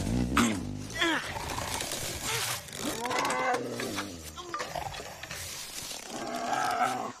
At 9:35, as Bumpy is sleeping in the dirt, a two-tone chime is heard for no reason. It sounds like the notification tone of an instant message arriving on a digital device.
Listen to the tone (at about 4.5 seconds in the clip):
message-tone.mp3